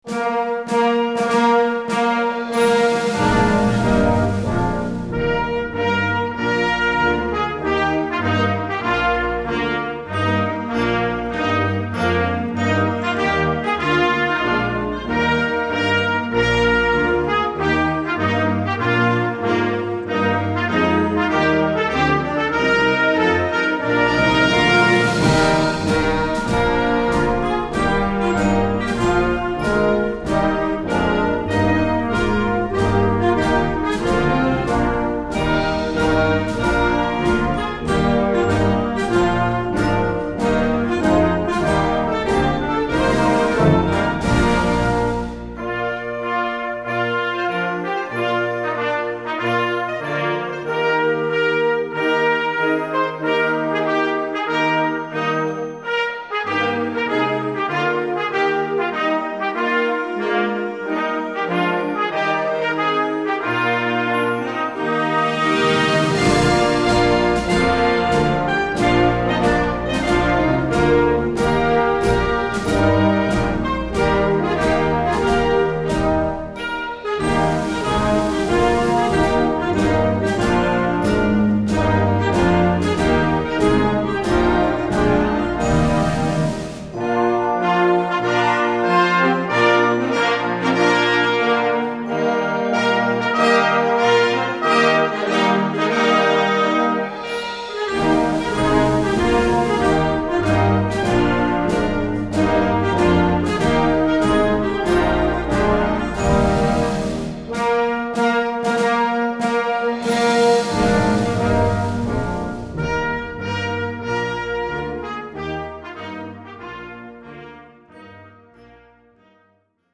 Concert Band ou Harmonie et 3 Trompettes